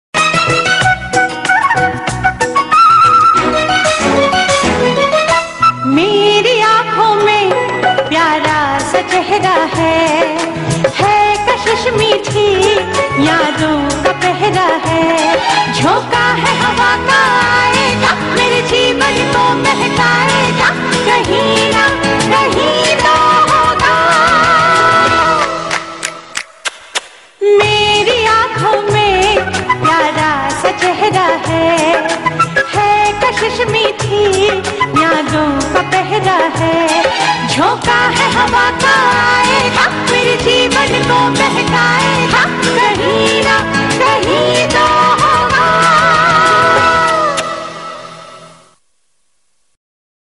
Categories TV Serials Tones